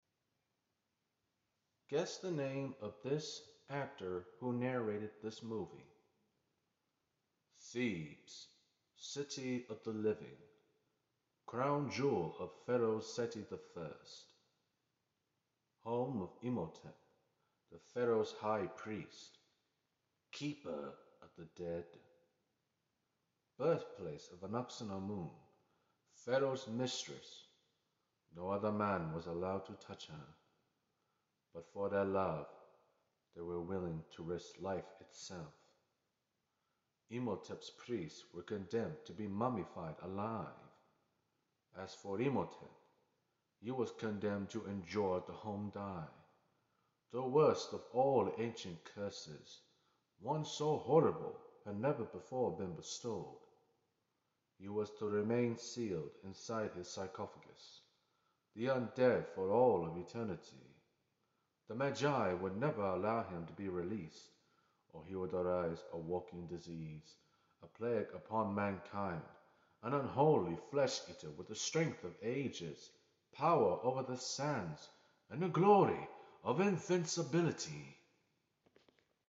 Guess The Actor voice impression